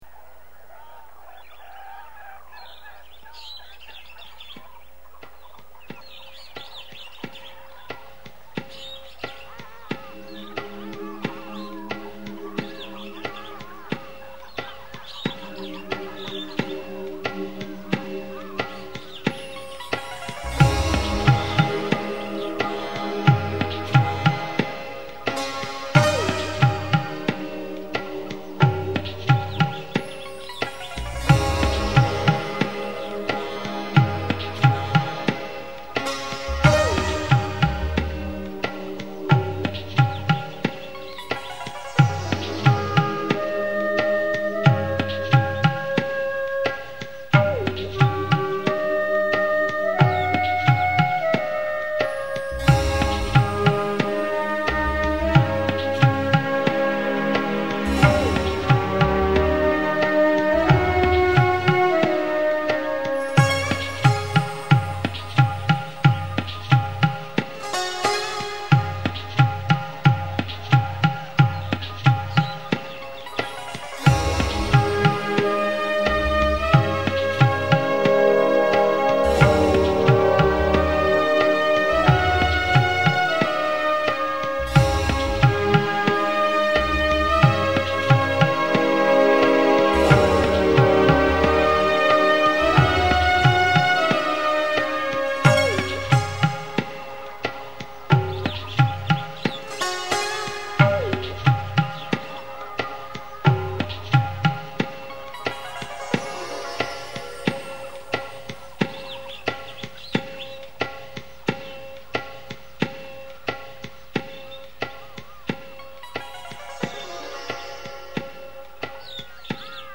Colonne sonore che fanno parte del gioco.